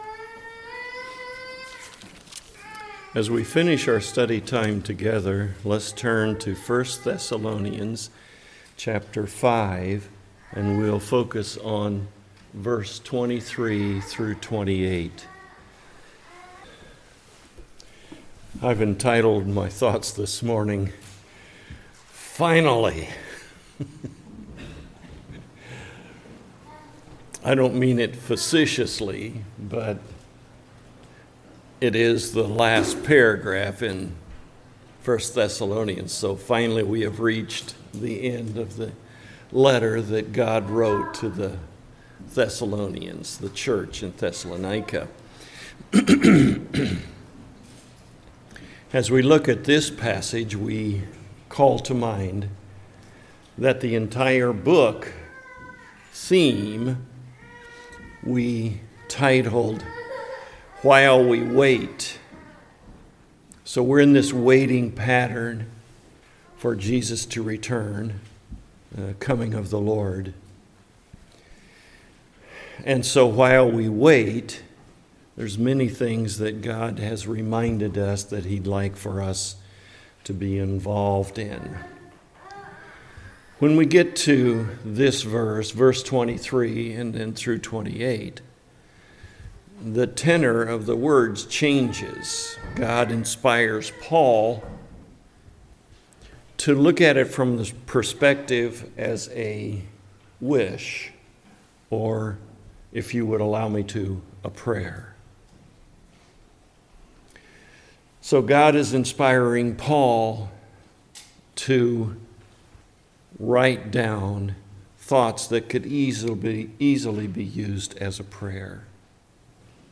1 Thessalonians Passage: 1 Thessalonians 5:25-28 Service Type: Morning Worship « Ready